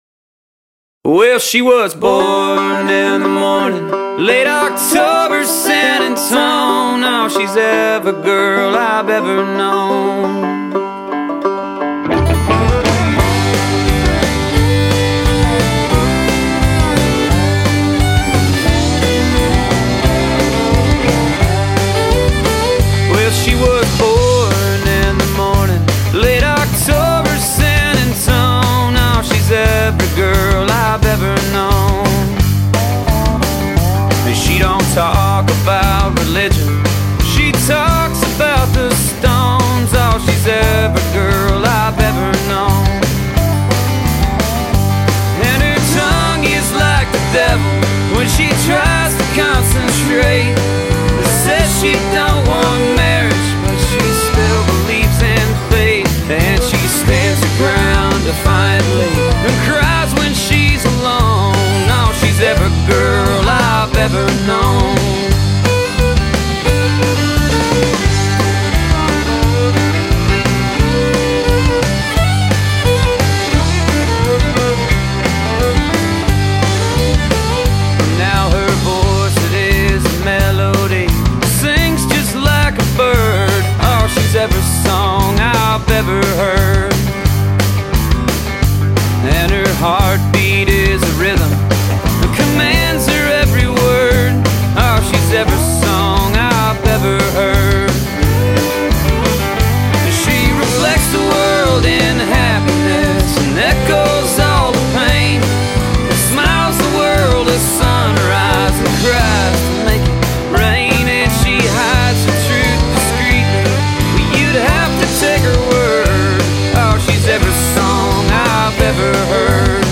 lead guitar
fiddle
bass
drums